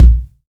INSKICK13 -R.wav